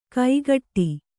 ♪ kaigaṭṭi